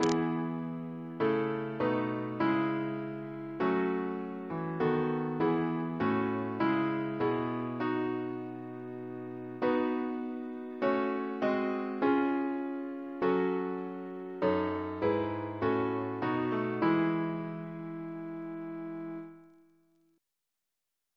Double chant in E Composer: Charles Hubert Hastings Parry (1848-1918) Reference psalters: ACP: 191; CWP: 43; RSCM: 110